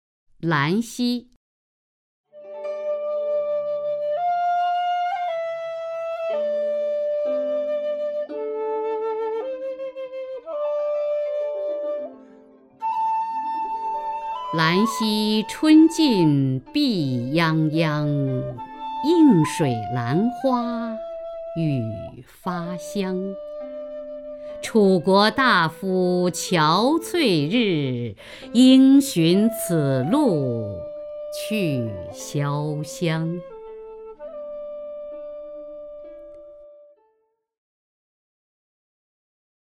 雅坤朗诵：《兰溪》(（唐）杜牧) （唐）杜牧 名家朗诵欣赏雅坤 语文PLUS